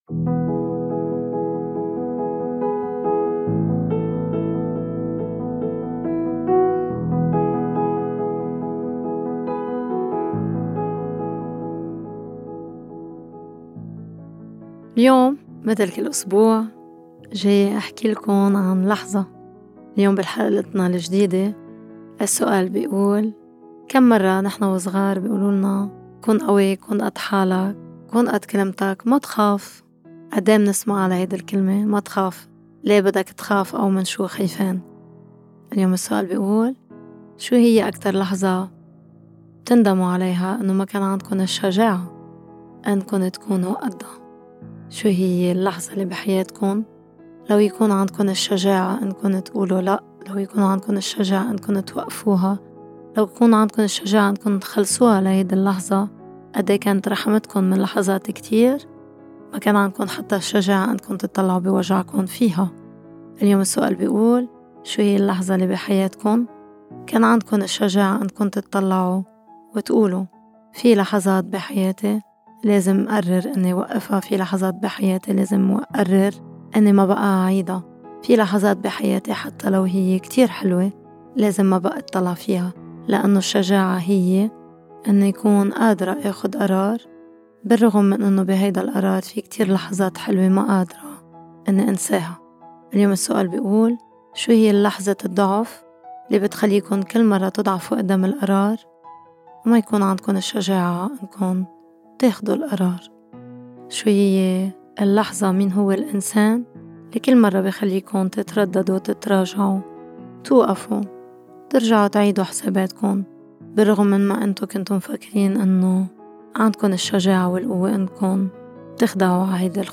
الجزء الثاني: مناقشة مع الضيف حول (شو هي اللحظة يلي بالحياة بتطلب شجاعة كتير كبيرة؟ )